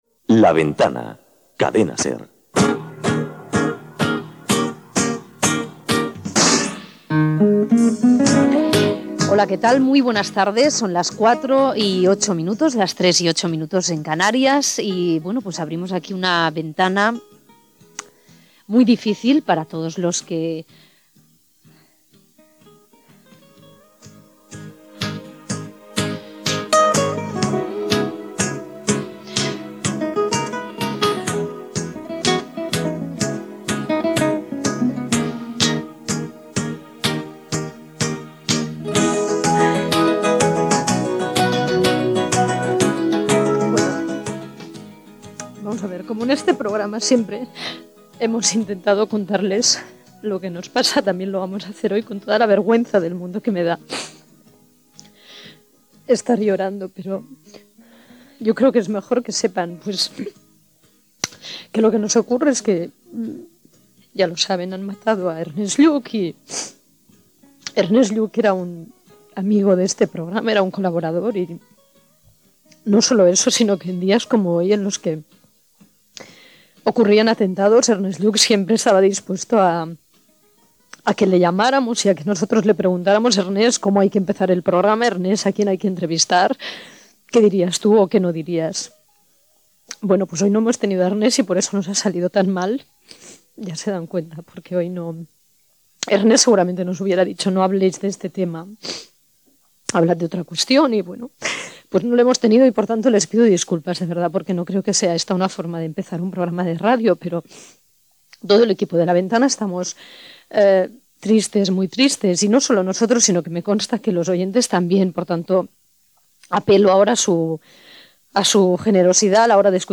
Indicatiu del programa, hora, presentació del programa en el qual s'informa de la mort del polític Ernest Lluch, col·laborador del programa. Intervenció telefònica del polític comunista Santiago Carrillo
Info-entreteniment